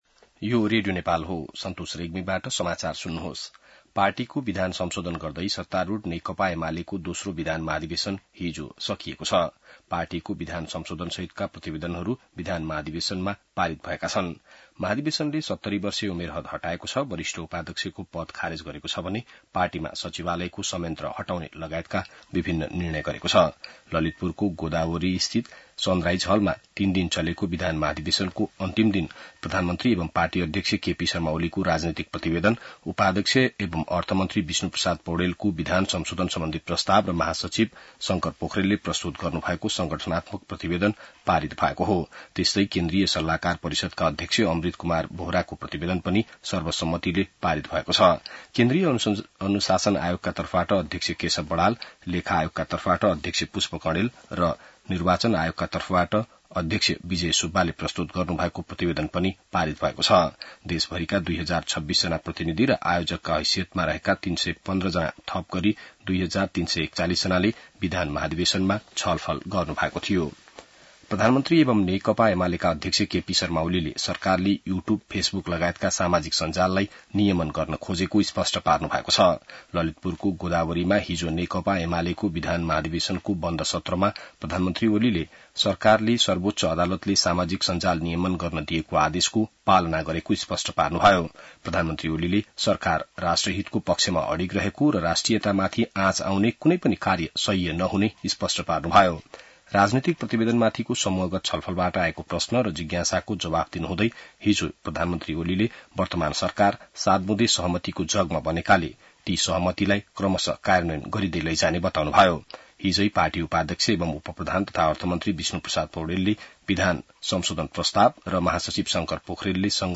बिहान ६ बजेको नेपाली समाचार : २३ भदौ , २०८२